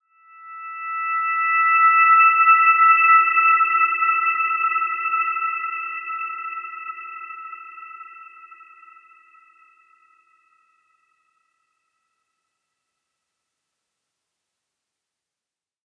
Dreamy-Fifths-E6-p.wav